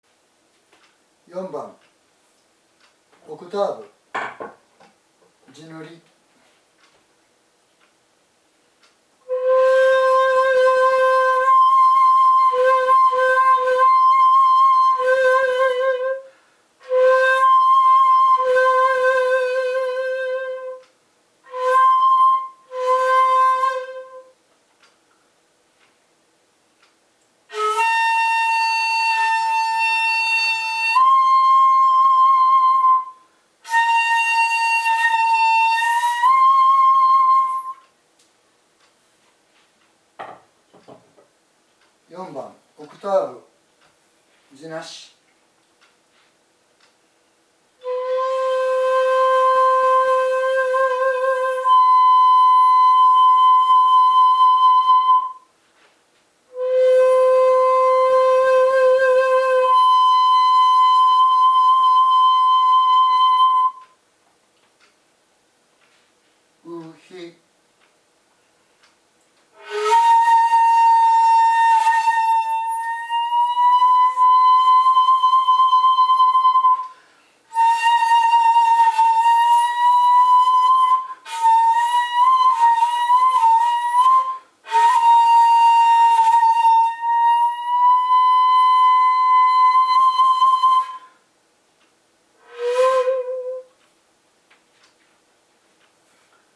今回は如何に地無し管で琴古流本曲が生き返ってくるか、を地塗り管と実際に吹き比べてみたいと思います。
４．オクターブが西洋音楽のように均等ではない。そのことにより幽玄な表現が出来る。
地塗り管で「乙り→甲ヒ」はフルートのようにきちんとしたオクターブになるが、地無し管ではその幅が狭く、若干「甲ヒ」が低めである。
本曲では「乙り→甲ヒ」を旋律型では使うことはないので、この低め甲ヒは狭いオクターブ効果により「幽玄」な雰囲気を醸し出す。